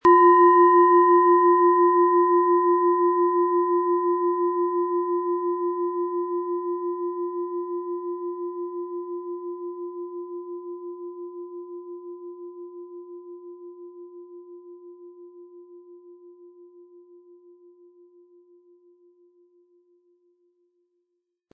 Tibetische Herz-Schulter-Bauch- und Kopf-Klangschale, Ø 16,1 cm, 320-400 Gramm, mit Klöppel
Kostenlos mitgeliefert wird ein passender Klöppel zur Schale, mit dem Sie die Töne der Schale gut zur Geltung bringen können.